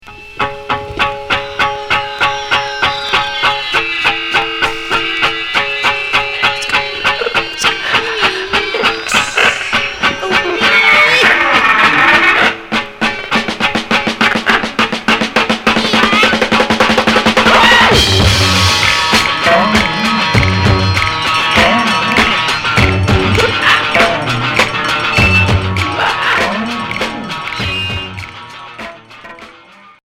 Expérimental Unique 45t retour à l'accueil